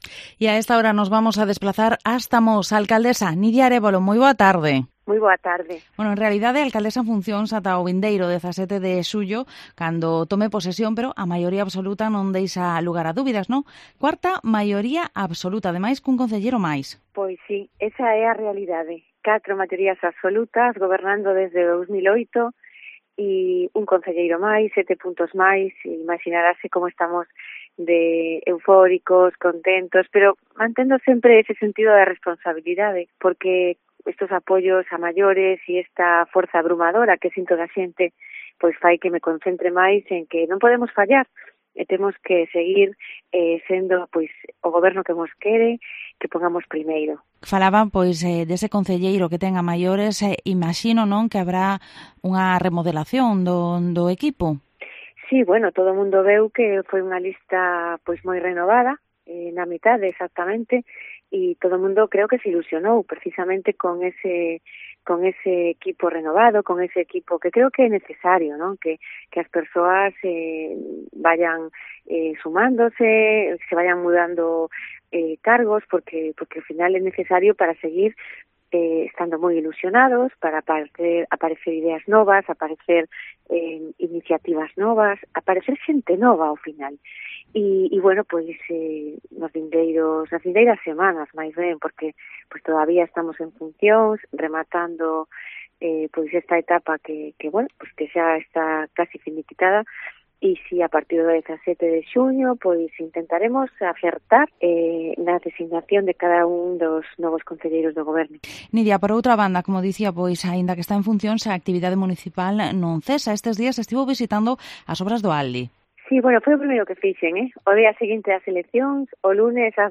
Entrevista a Nidia Arévalo, Alcaldesa en funciones de Mos